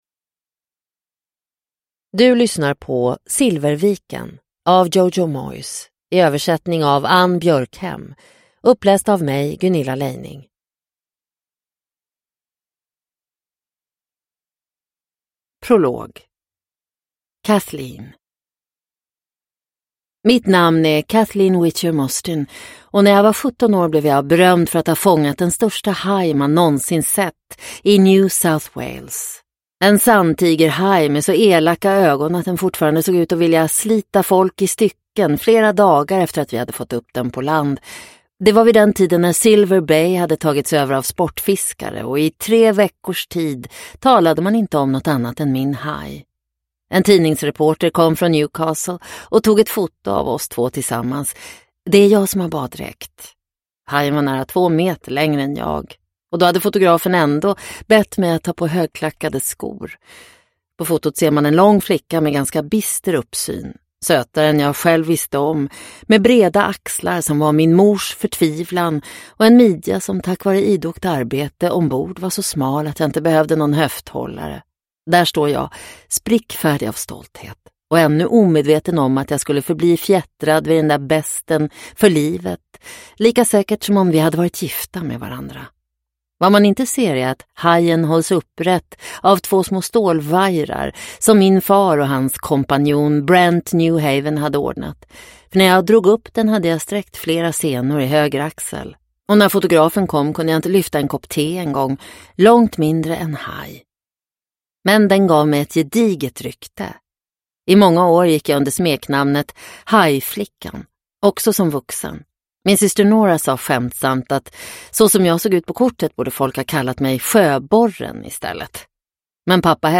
Silverviken – Ljudbok – Laddas ner